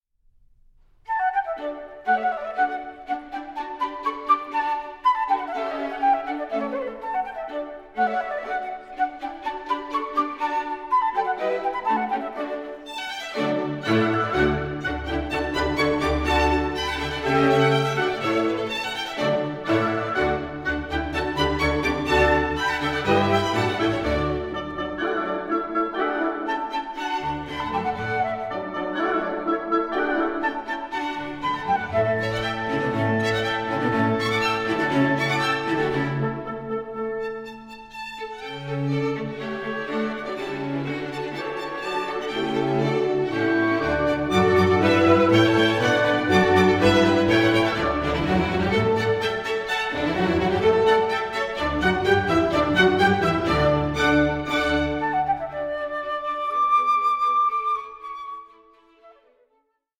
Concerto for Flute and Orchestra No.2 in D major